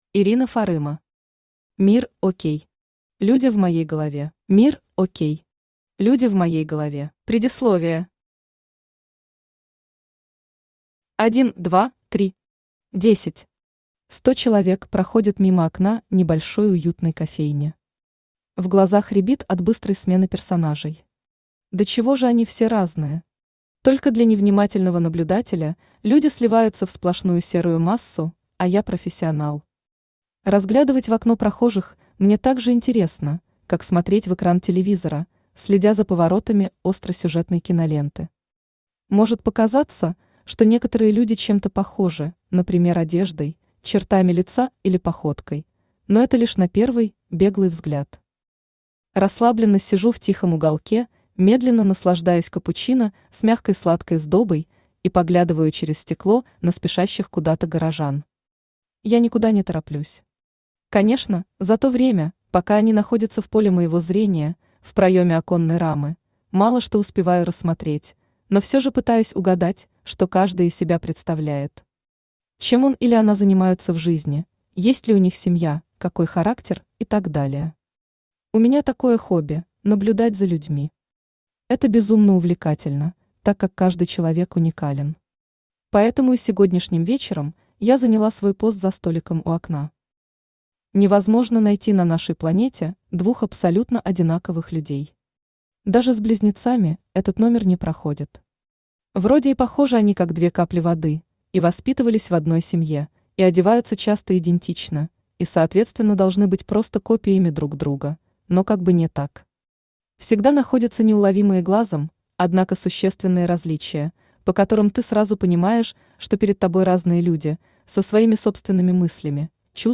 Aудиокнига Мир-Ок. Люди в моей голове Автор Ирина Фарыма Читает аудиокнигу Авточтец ЛитРес. Прослушать и бесплатно скачать фрагмент аудиокниги